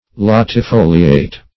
Search Result for " latifoliate" : The Collaborative International Dictionary of English v.0.48: Latifoliate \Lat`i*fo"li*ate\, Latifolious \Lat`i*fo"li*ous\, a. [L. latifolius; latus broad + folium leaf: cf. F. latifoli['e].]
latifoliate.mp3